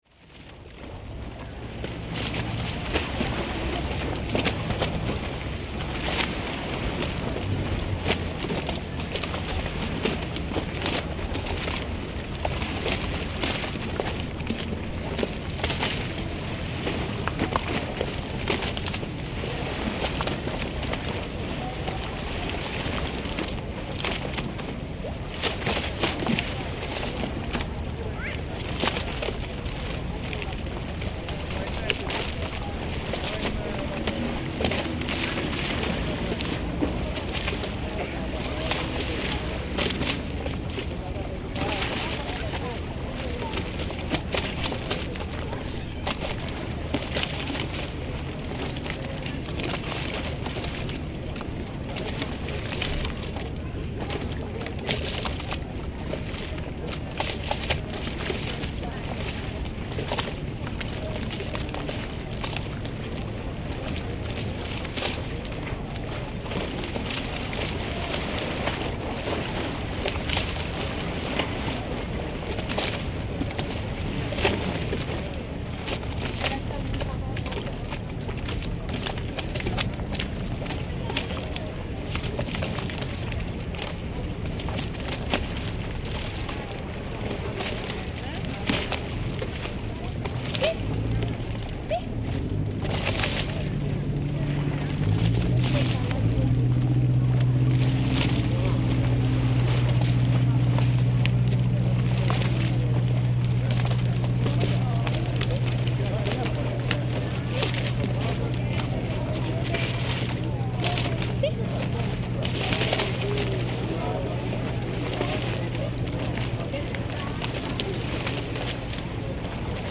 Facebook Twitter Headliner Embed Embed Code See more options Looking out from Piazza San Marco in Venice across the lagoon, there is a regular mooring spot for many of the city's iconic gondolas, and they rock back and forth, sloshing gently along as motor boat traffic stirs the waters nearby. Here we can hear the gondolas knocking into one another and riding the waves, as motorboats pass on one side, and passers-by walk behind us from another angle. Binaural recording by Cities and Memory.